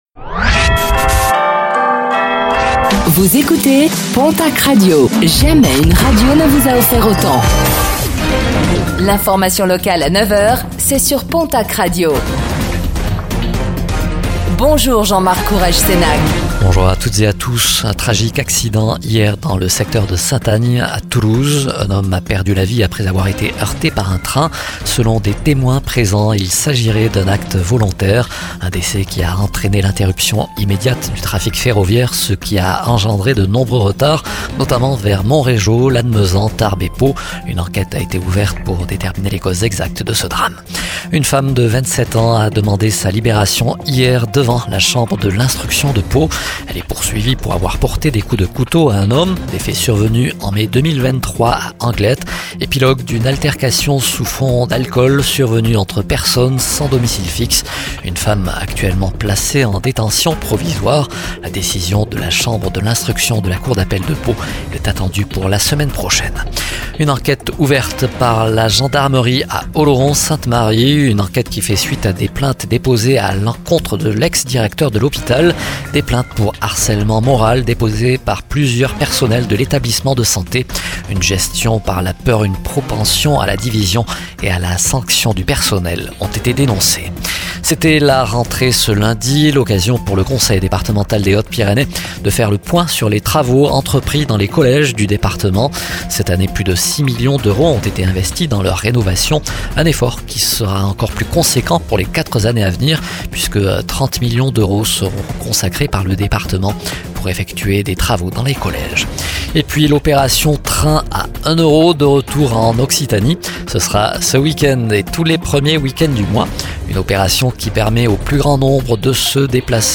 Réécoutez le flash d'information locale de ce mercredi 04 septembre 2024